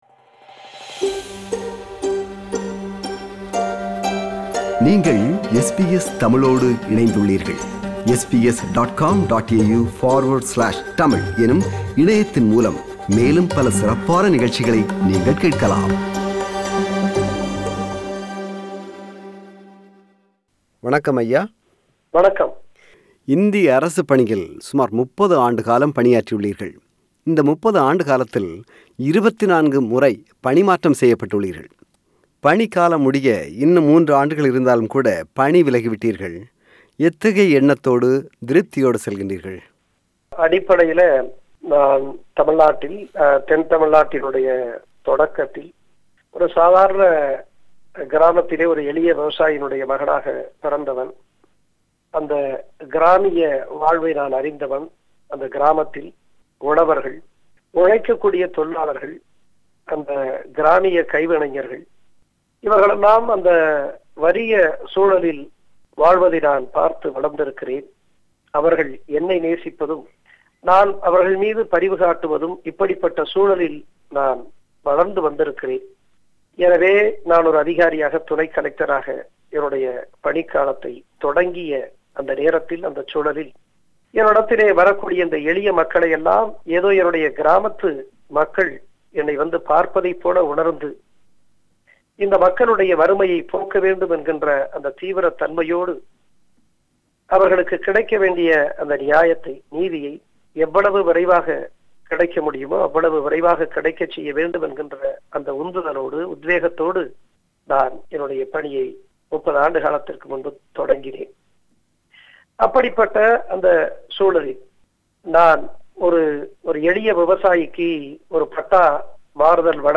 தமிழ் இனம், மண், மக்கள், மொழி, மரபு என்று சிந்திக்கும் சகாயம் IAS அவர்கள் கடந்த வாரம் பணிவிலகினார். அவரது, திருப்தி, வலி, எதிர்கால திட்டம் என்று மனம் திறந்து உரையாடுகிறார்.